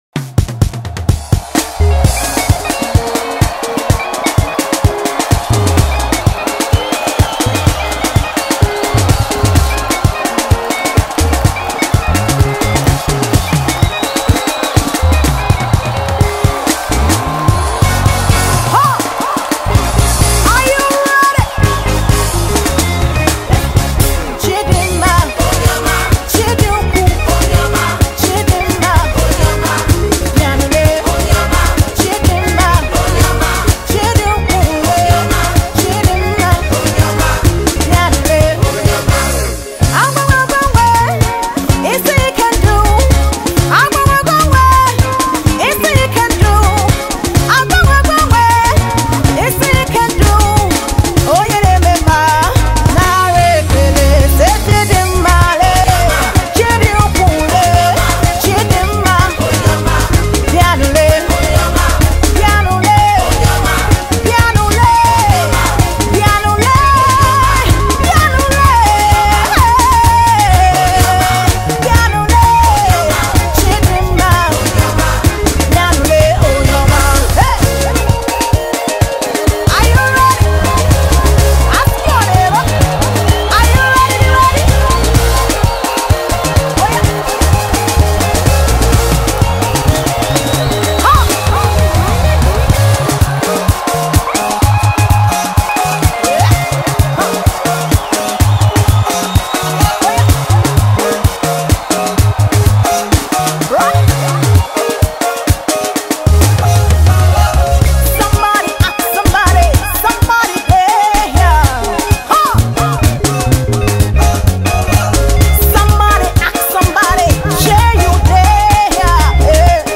Christian & Gospel SongsNigerian Gospel Songs
soul-lifting gospel song
Genre:Gospel